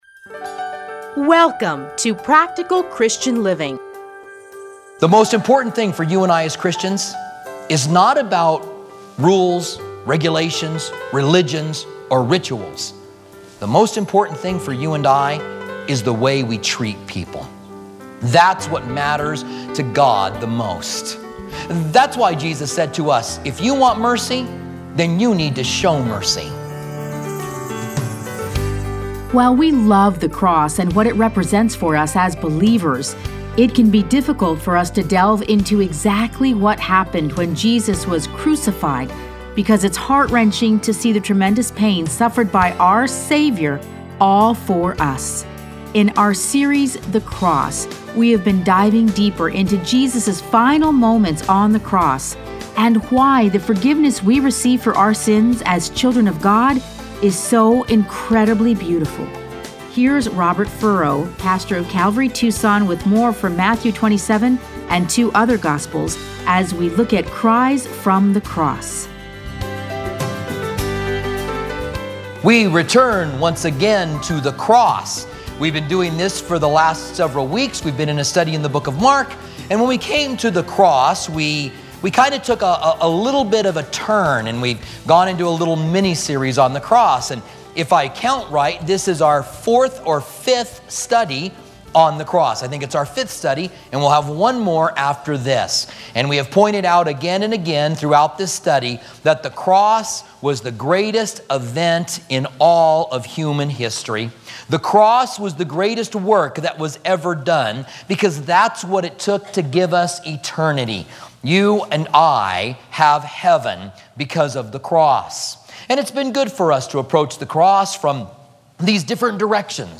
Listen to a teaching from John 19:25-27 and Luke 23:32-34,39-43.